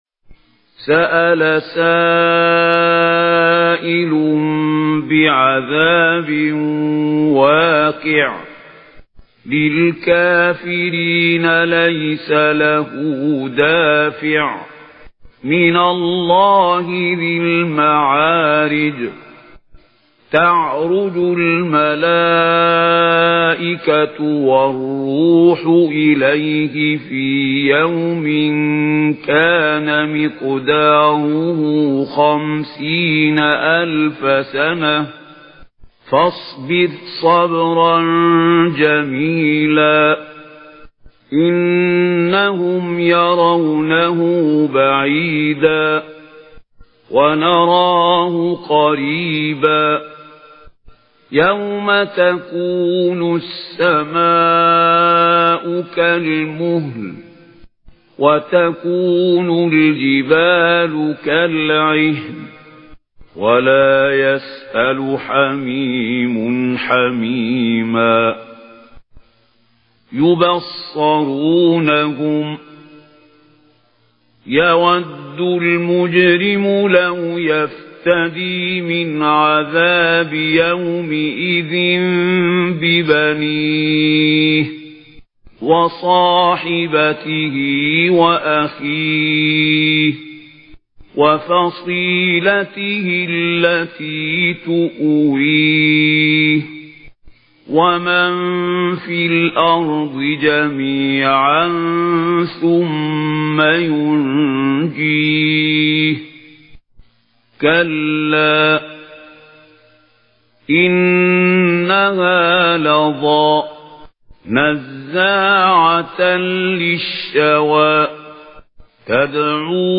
Audio Quran Tarteel Recitation